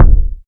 KICK.137.NEPT.wav